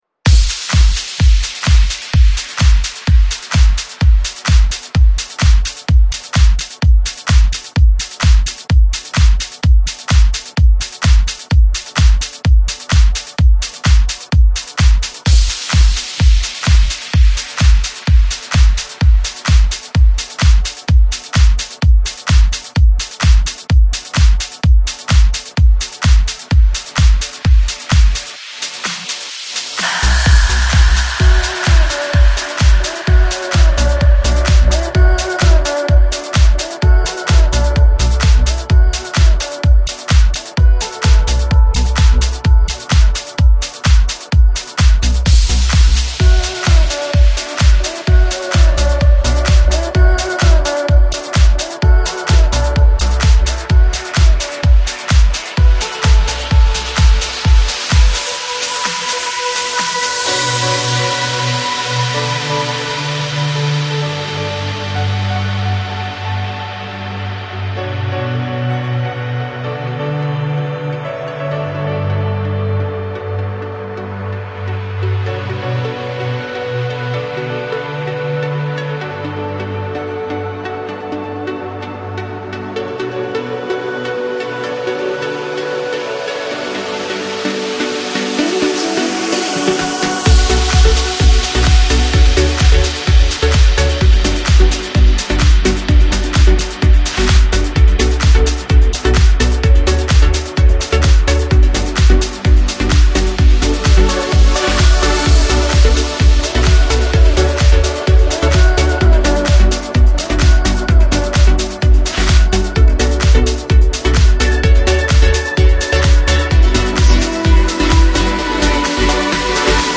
Стиль: Melodic Progressive